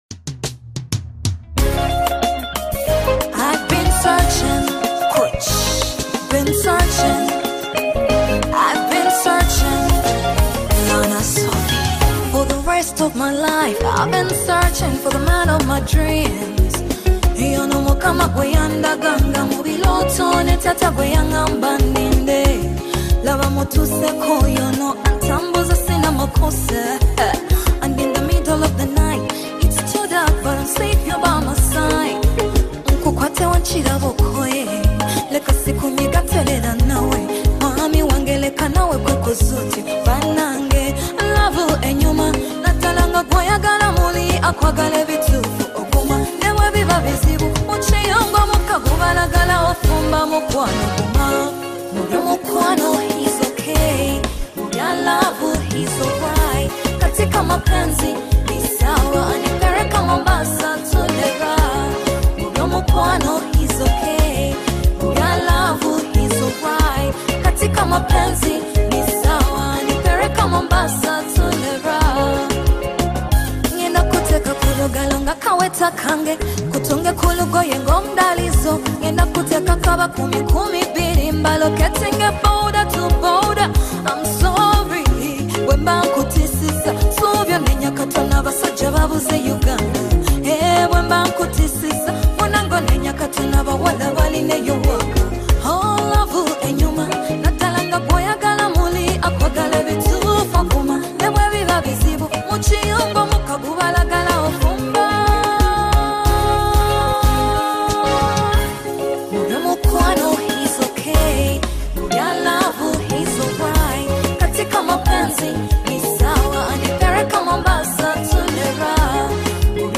Genre: RNB